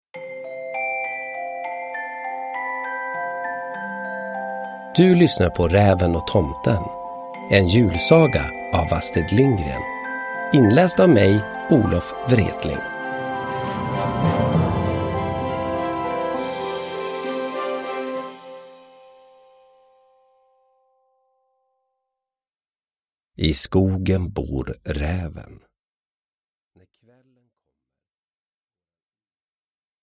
Vänta på julen och lyssna på Olof Wretling när han läser en mysig julsaga av Astrid Lindgren.
Uppläsare: Olof Wretling